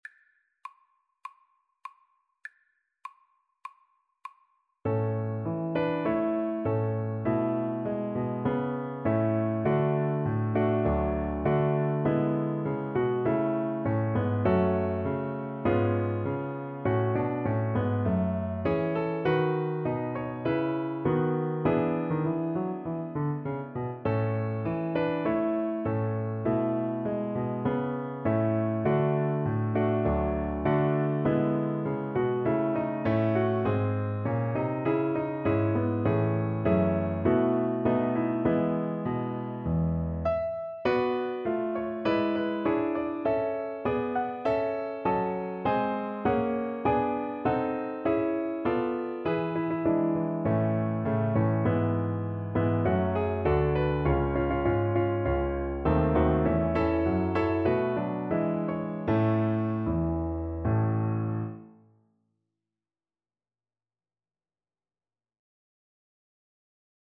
Play (or use space bar on your keyboard) Pause Music Playalong - Piano Accompaniment Playalong Band Accompaniment not yet available transpose reset tempo print settings full screen
Traditional Music of unknown author.
A major (Sounding Pitch) (View more A major Music for Violin )
4/4 (View more 4/4 Music)